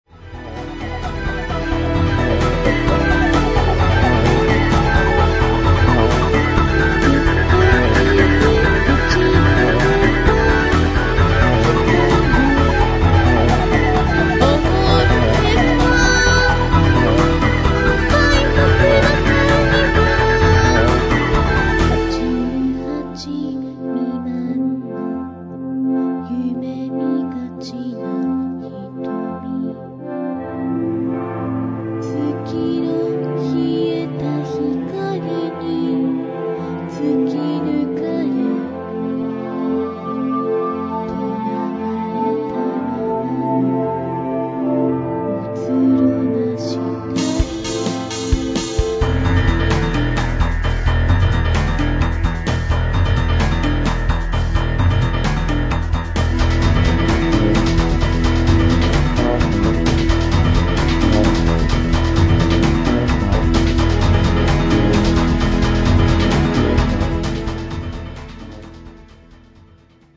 電脳空間を浮遊する女声Voが物語る博物誌